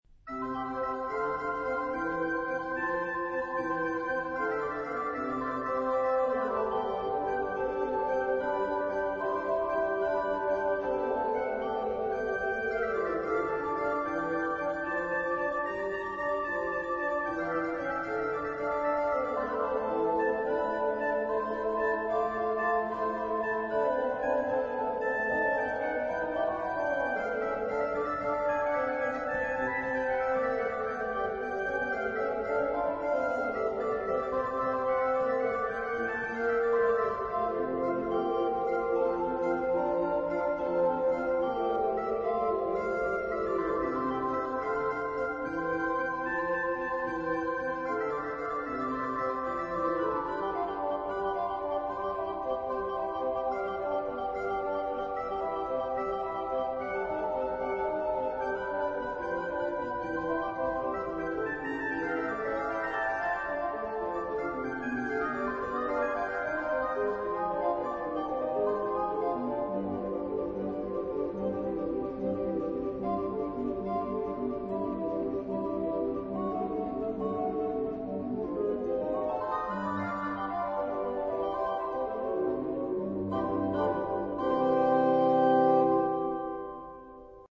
巴哈平均律管風琴版
聽來有節慶的喜悅，也有內心的歡愉，因為感受到平安。
晚安曲選的這兩段，都是前奏曲，且是比較小巧般的。
使用了四個教堂的四架管風琴，